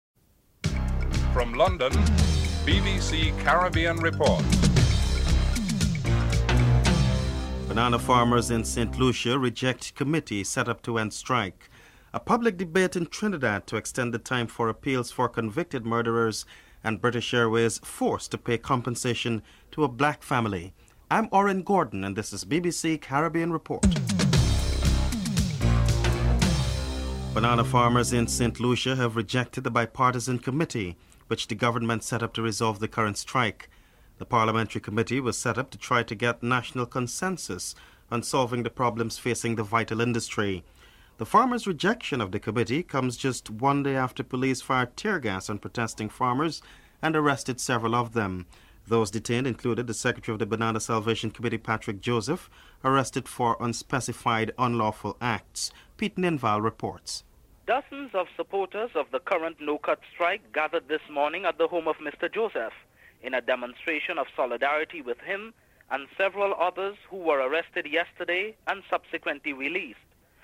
President Cheddi Jagan is interviewed (03:15-06:08)
Member of Parliament Bernie Grant is interviewed (10:48-12:35)